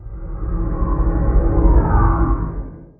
minecraft / sounds / mob / guardian / elder_idle2.ogg
elder_idle2.ogg